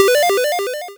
TetrisLine1.wav